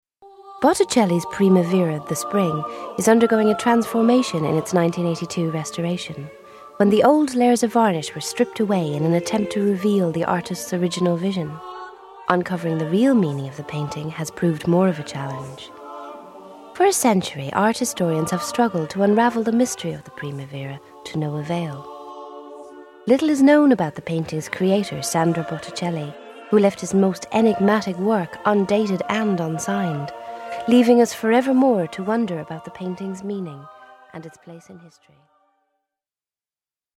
• Female
Accent
• Irish
Soft and intelligent